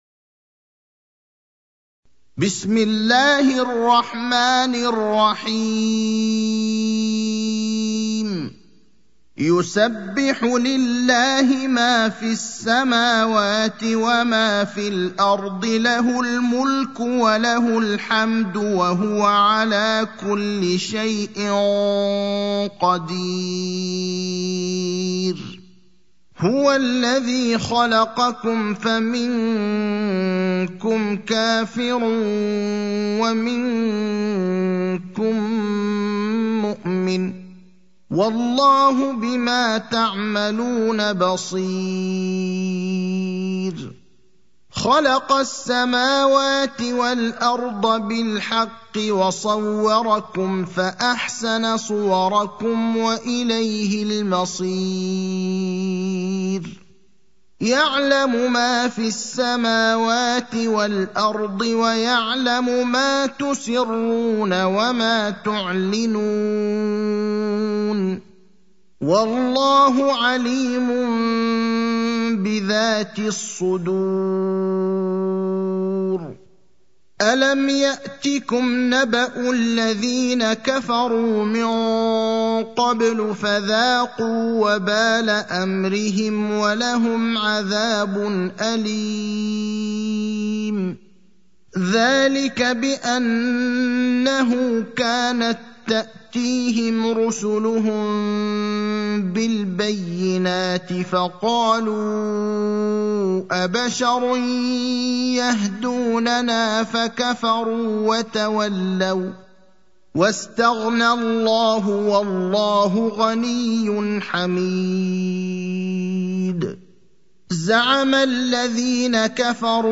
المكان: المسجد النبوي الشيخ: فضيلة الشيخ إبراهيم الأخضر فضيلة الشيخ إبراهيم الأخضر التغابن (64)